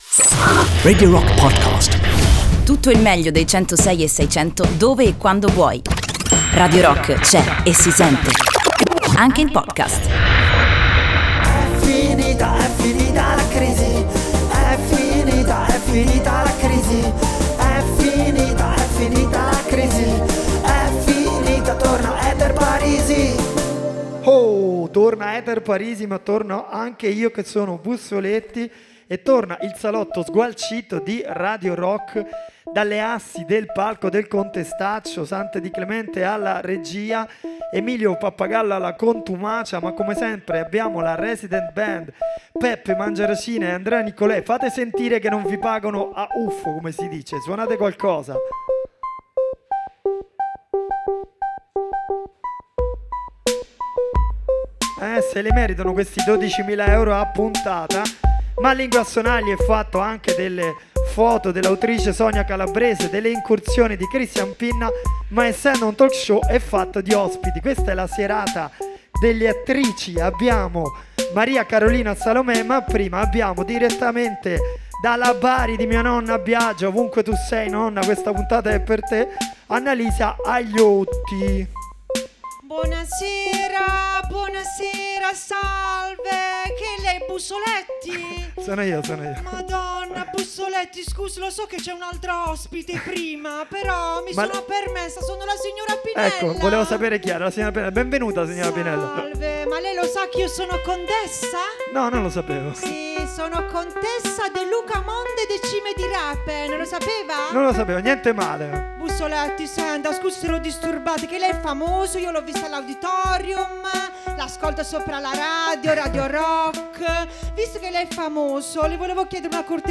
dal palco del Contestaccio